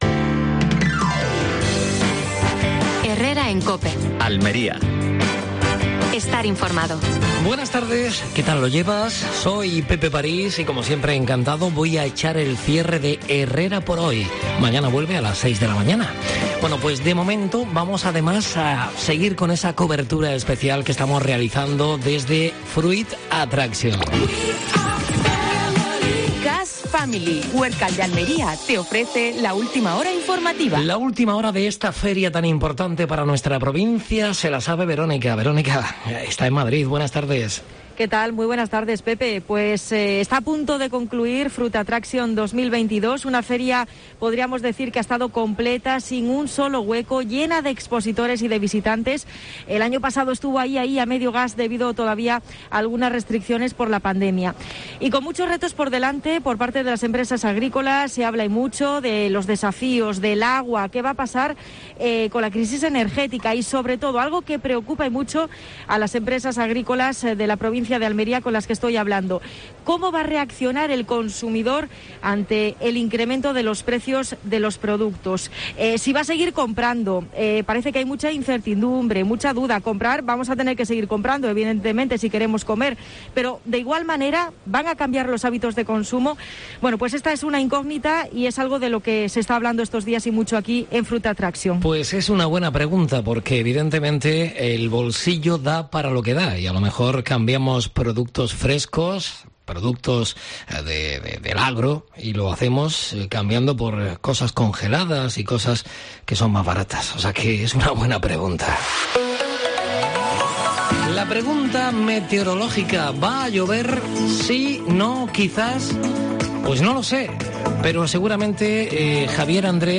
Última hora en Almería. Previsión del tiempo. Estado de las carreteras y de la mar.